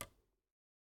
button_hover.ogg